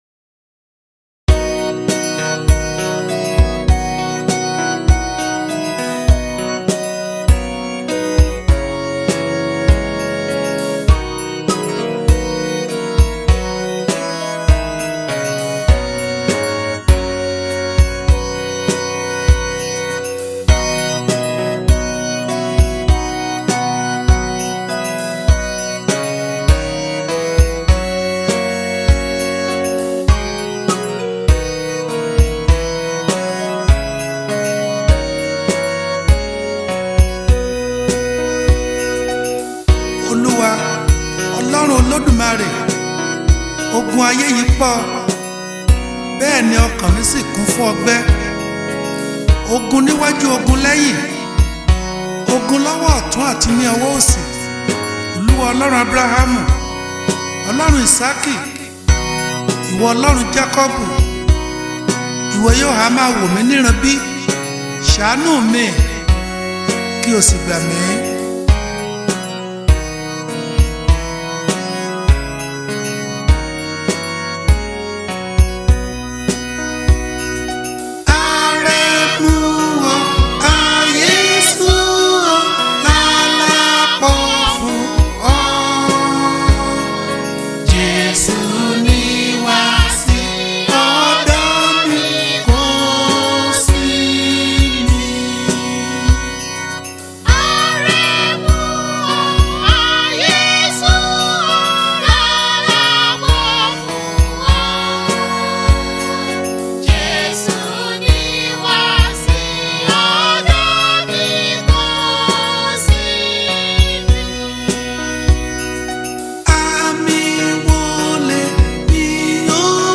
March 31, 2025 Publisher 01 Gospel 0